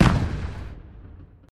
firework_distance_02.ogg